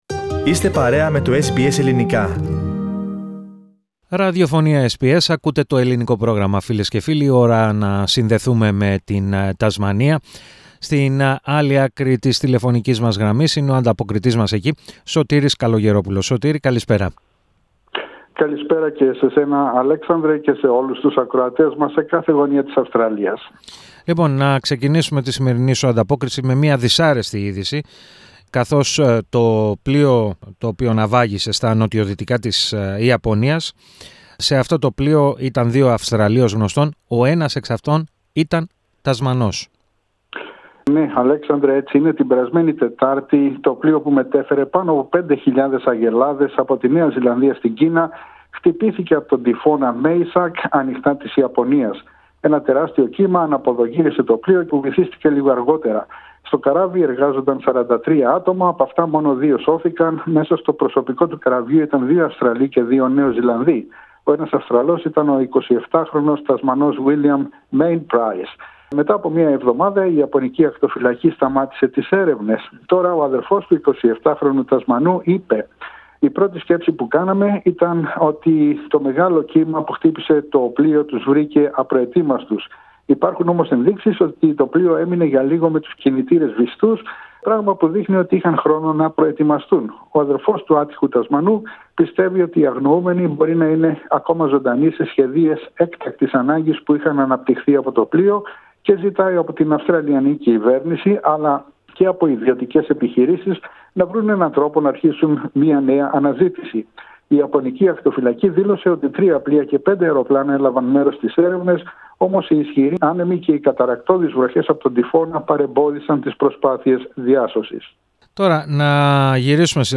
Περισσότερα ακούμε στην ανταπόκριση